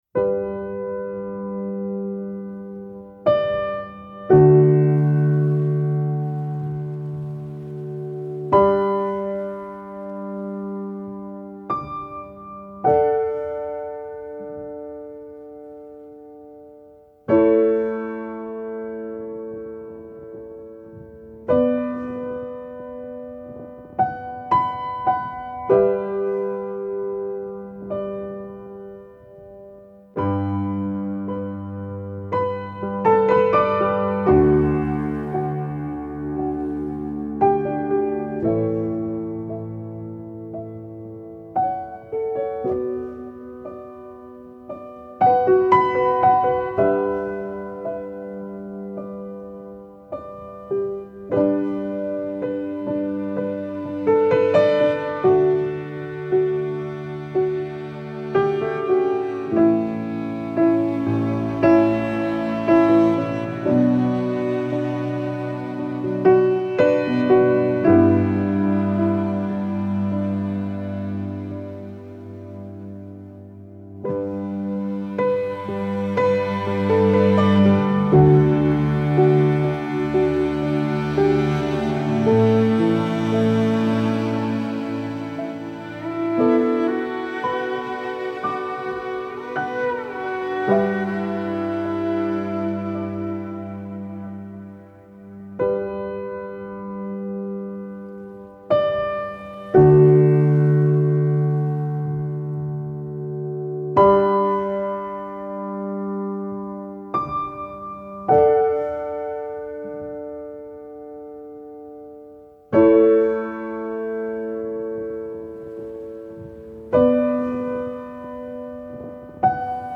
آرامش بخش
Classical Crossover
پیانو , غم‌انگیز